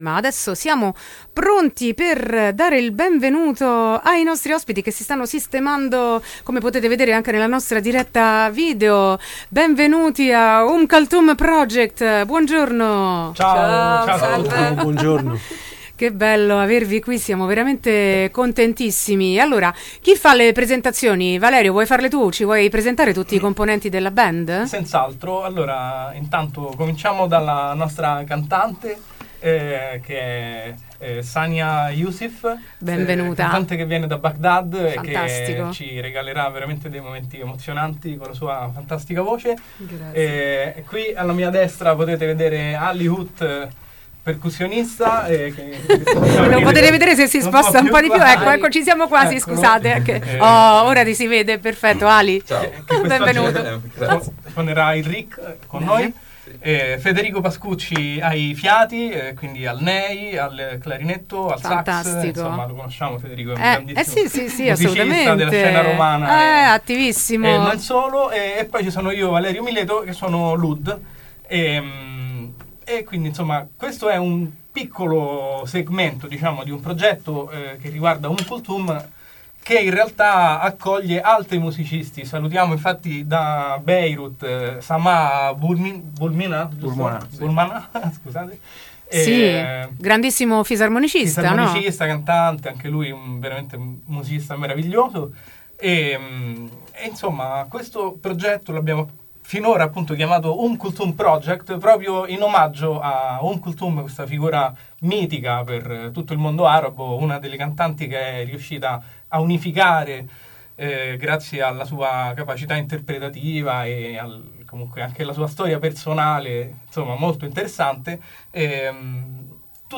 La voce più amata del mondo arabo: Intervista e minilive Umm Kulthum Project | Radio Città Aperta
al riq
al nay e clarinetto
all’oud
con un coinvolgente minilive in studio
intervista-minilive-ummkulthum-project-3-1-25.mp3